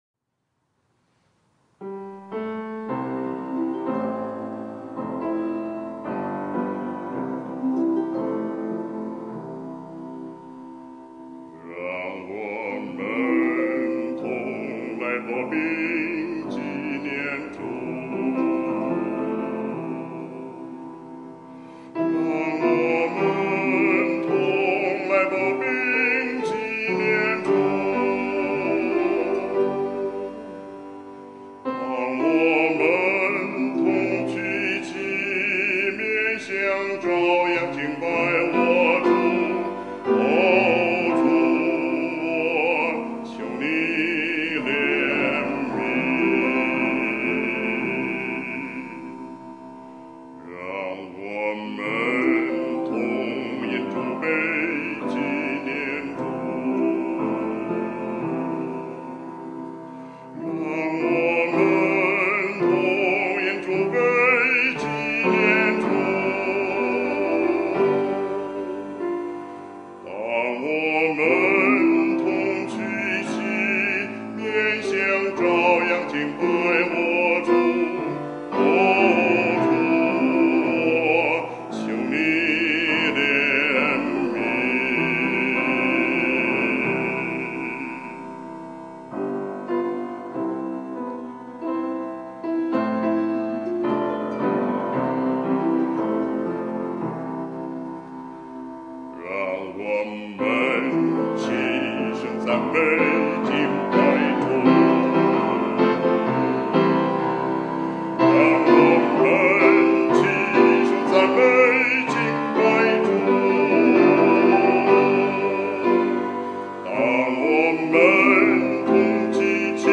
赞美诗《同来擘饼》 我当日传给你们的，原是从主领受的，就是主耶稣被卖的那一夜，拿起饼来，祝谢了，就擘开，说：“这是我的身体，为你们舍的。